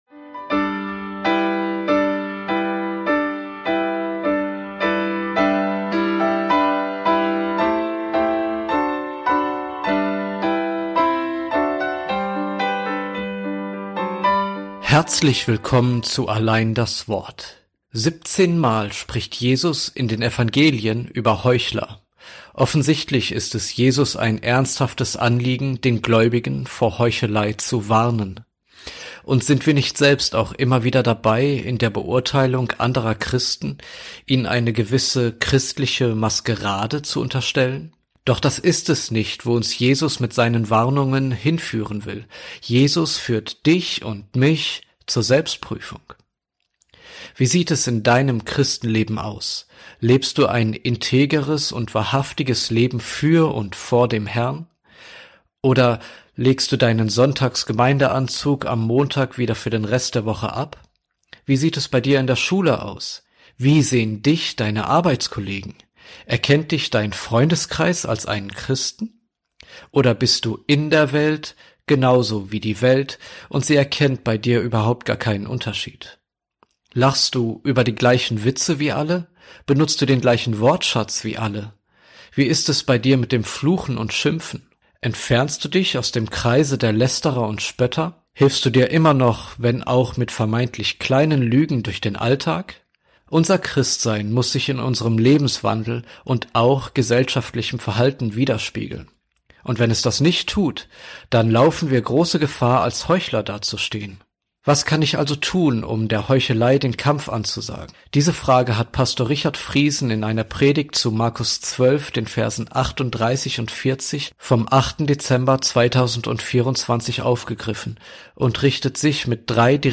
Allein das Wort - Konferenz '24 24 okt 2024 · Allein das Wort